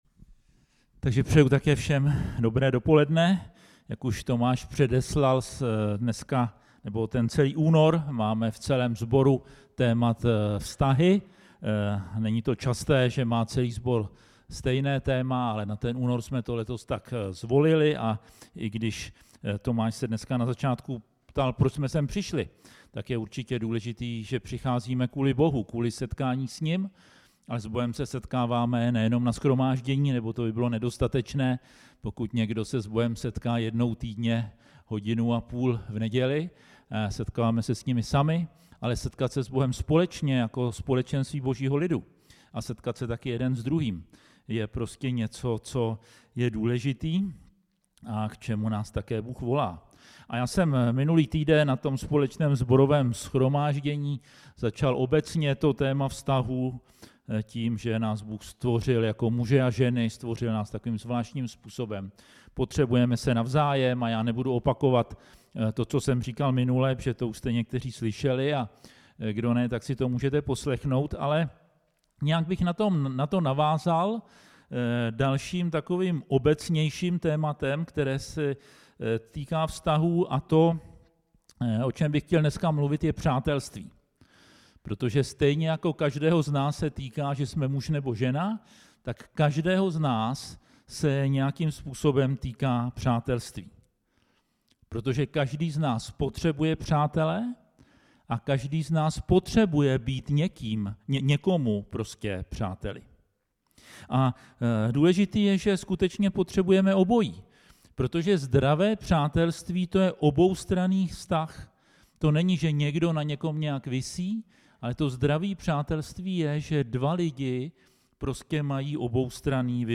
Sam. 20, 12 – 13 Stáhnout MP3 Přehrát kázání online 0:00:00 23:42 Další podobná kázání Proměna a růst charakteru Církev, vztahy věřících Ježíše potřebujeme znát a přijmout jako Spasitele i Pána.